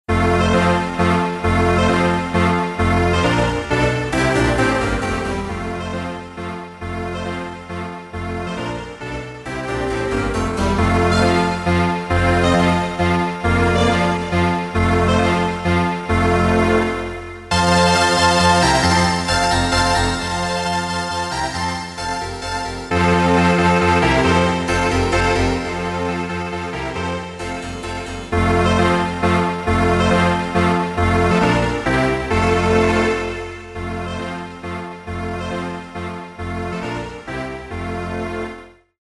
12楽章からなる協奏曲。
クラシック